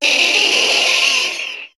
Cri de Parasect dans Pokémon HOME.